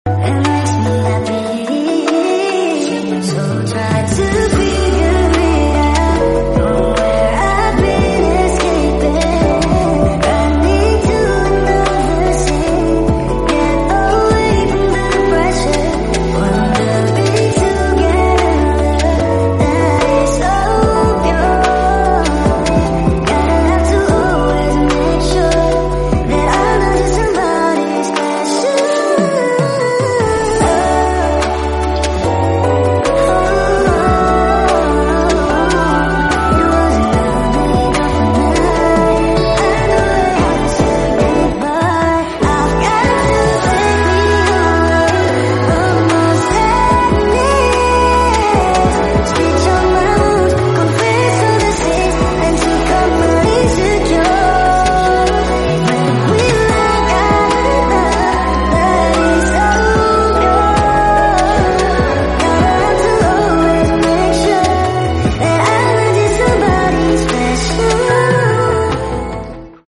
Bola Volly nya di tendang sound effects free download